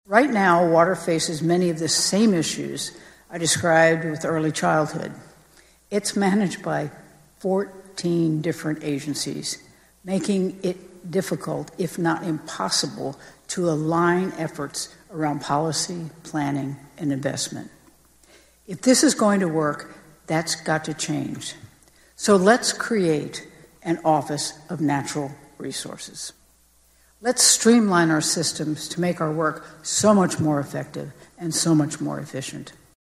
The governor used her annual address as a “quarterly progress report” with an eye towards the rest of the century and how Kansas can flourish in the future. One area where she expects bipartisan movement is water, especially by restructuring the current bureaucracy.